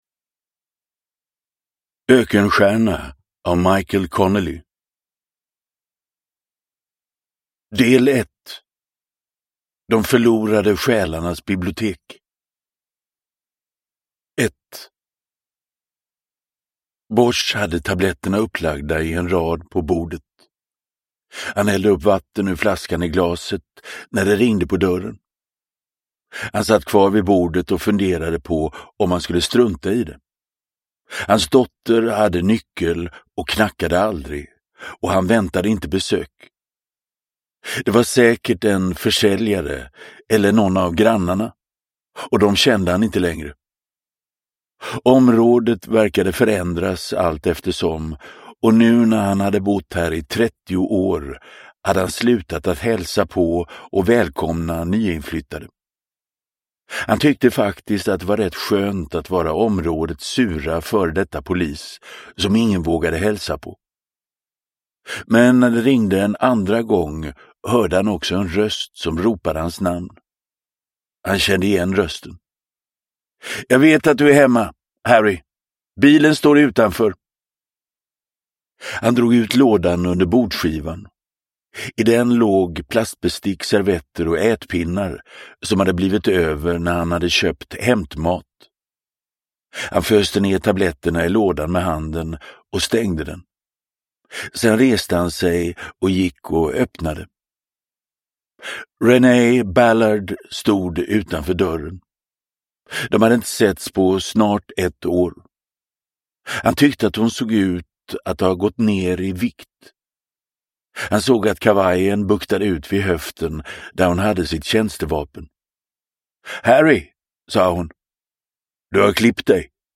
Ökenstjärna – Ljudbok – Laddas ner
Uppläsare: Magnus Roosmann